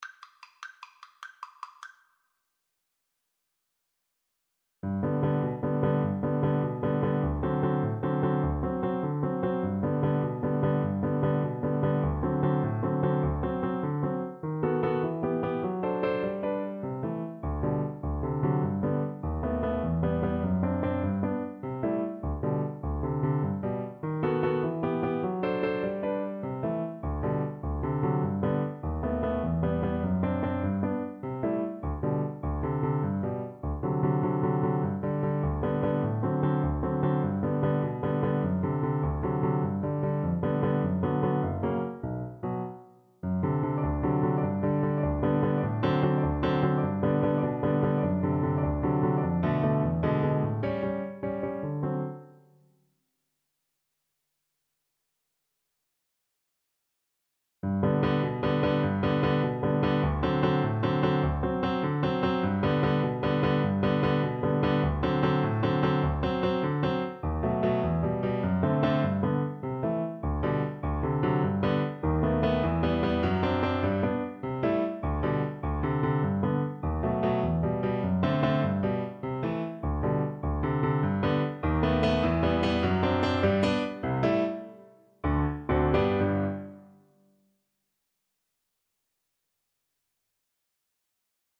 3/4 (View more 3/4 Music)
Molto vivace .=100
Classical (View more Classical Cello Music)